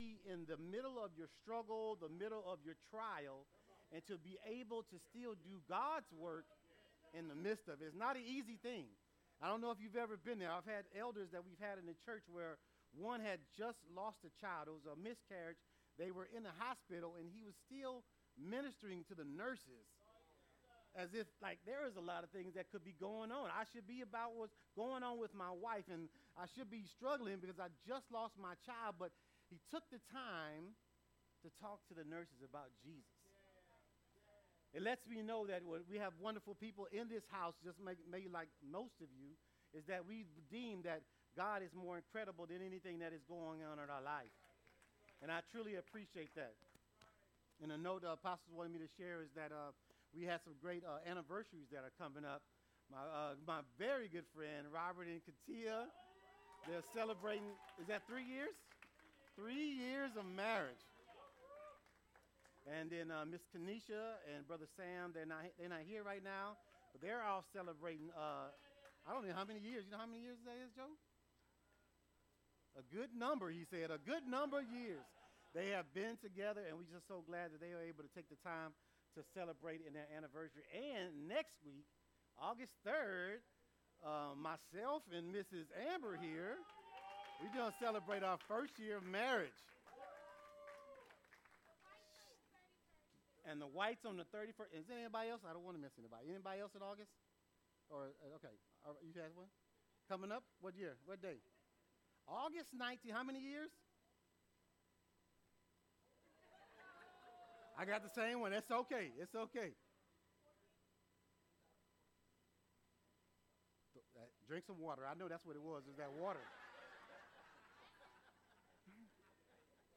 Welcome Back, Wellness Warrior, for an amazing conversation today AND to have a PARTYYYY!! 🥳 This conversation is a real treat today!!